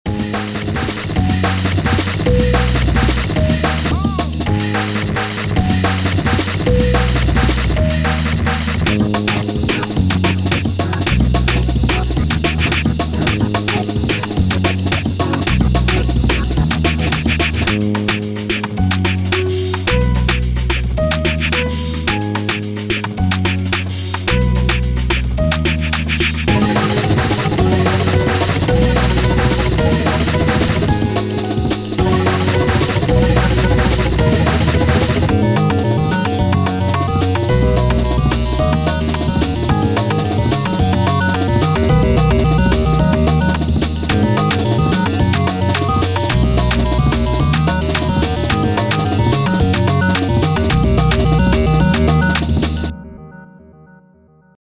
electronic drums experimental